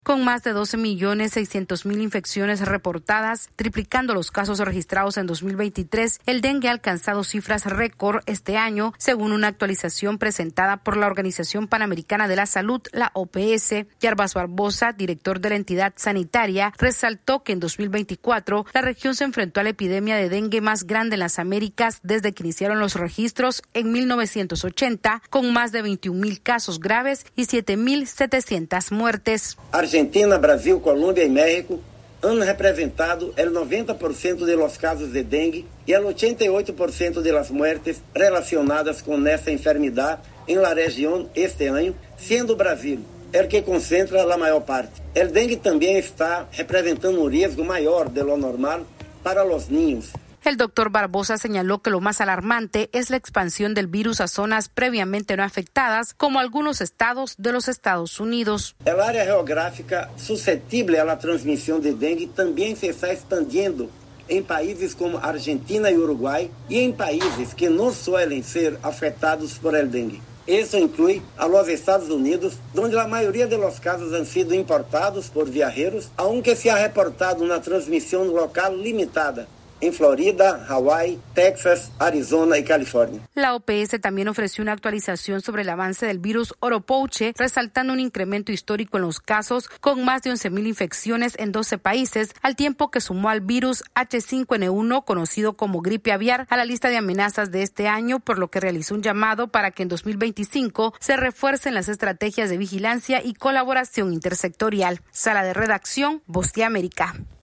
AudioNoticias
La región termina un año récord en casos de dengue con más de 12 millones de infecciones reportadas según datos de la Organización Panamericana de la Salud. Esta es una actualización de nuestra Sala de Redacción.